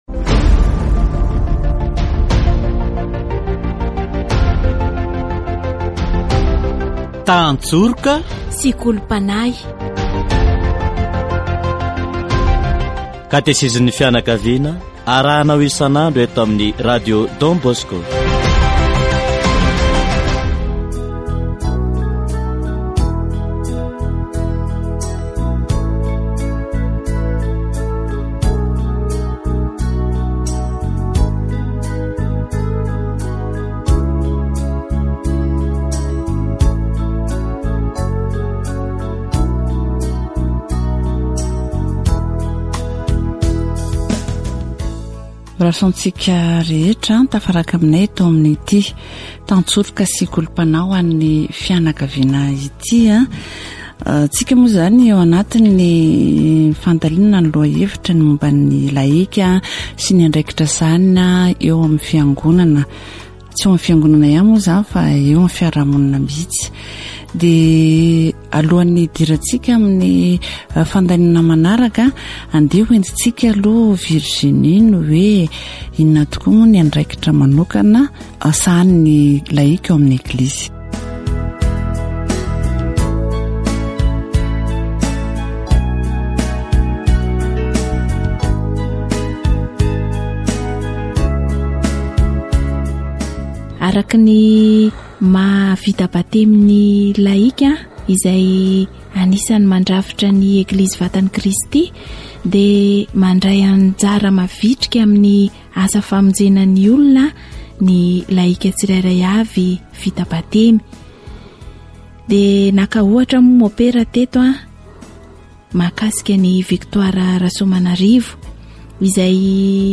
Les laïcs ont été appelés à être responsables de tout sur terre. Catéchèse sur la communion des baptisés